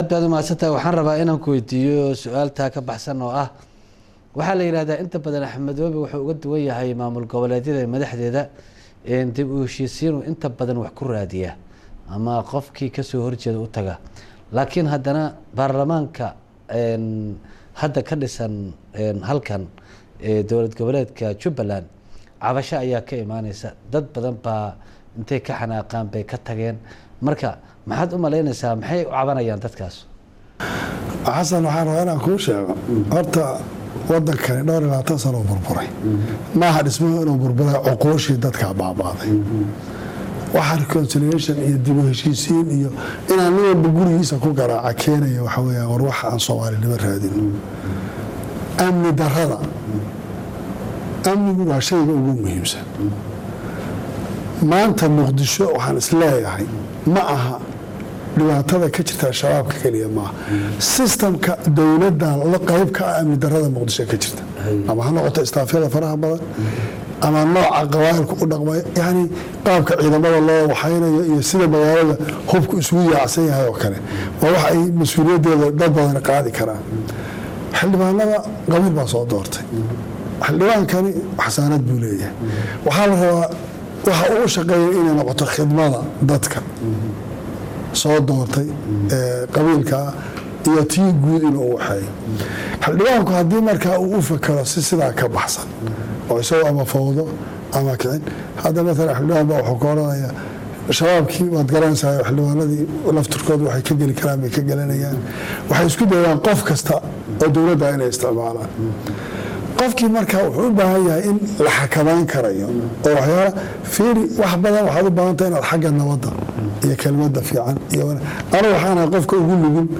13 June 2017 (Puntlandes) madaxweynaha Jubaland Axmed Madoobe ayaa isagoo uu xanaag ka muuqdo ka jawaabay eedayntii ay usoo jeediyeen labadii Xildhibaan ee kala ah Xildhibaan Cabdikadir Ibrahim Cali iyo Xildhibaan Maxamed Calili Yuusuf ee uu dhawaan ka saaray Baarlamaanka Jubaland. waan sharci daro in Xildhibaan sidaas xilka looga qaado.